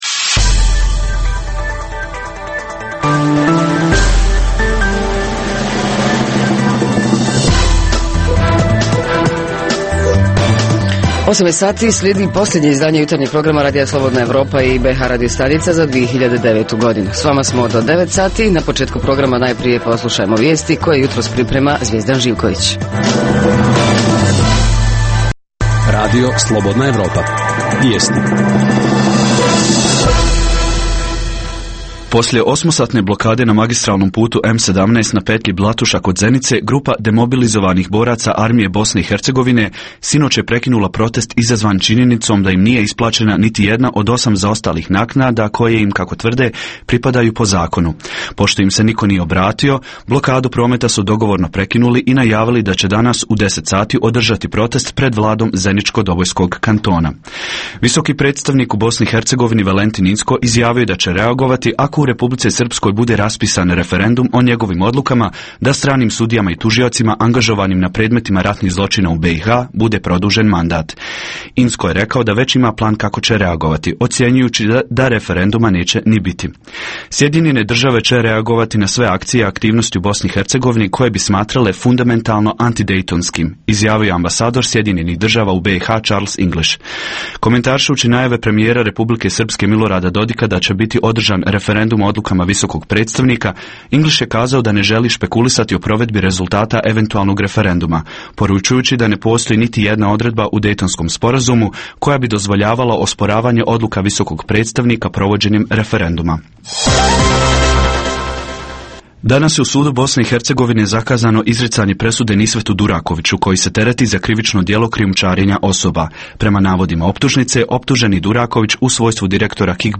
Pitamo: sprema li se neki novogodišnji program na otvorenom? Reporteri iz cijele BiH javljaju o najaktuelnijim događajima u njihovim sredinama.
Redovni sadržaji jutarnjeg programa za BiH su i vijesti i muzika.